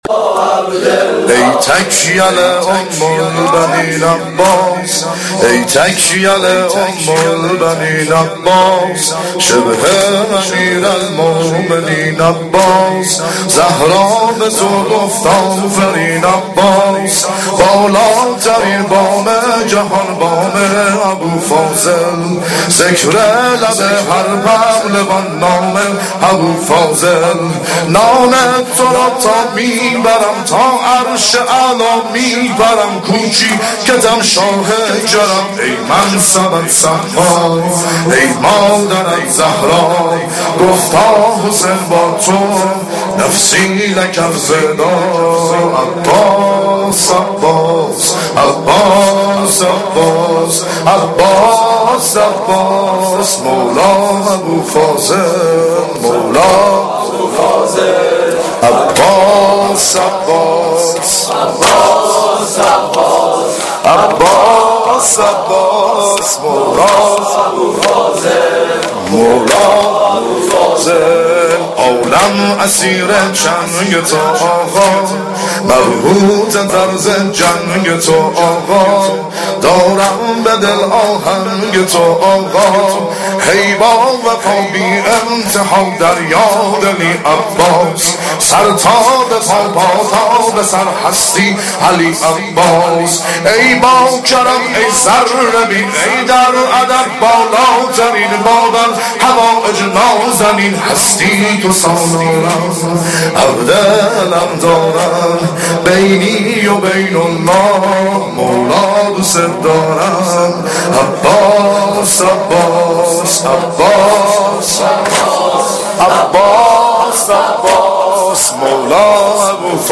صوت مداحی